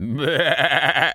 sheep_baa_bleat_10.wav